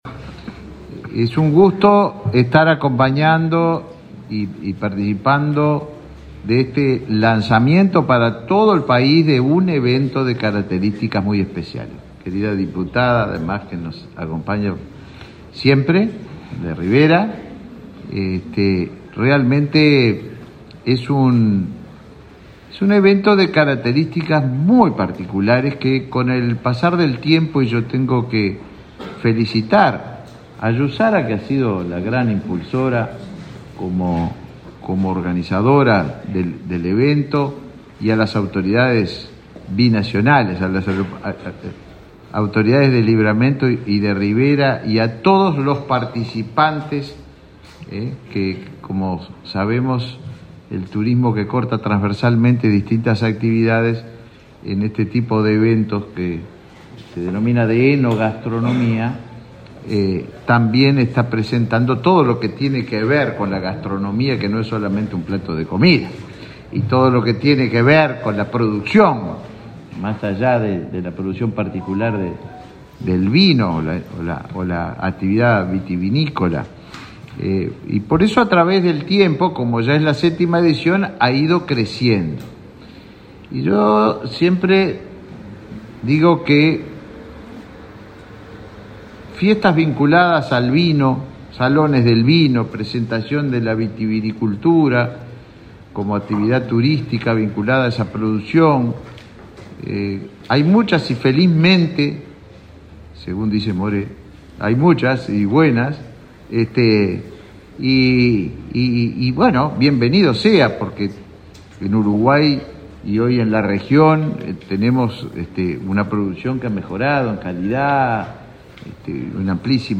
Palabras de autoridades en lanzamiento de Festival Binacional de Enogastronomía
El ministro Tabaré Viera y el intendente interino José Mazzoni mencionaron la trascendencia del evento.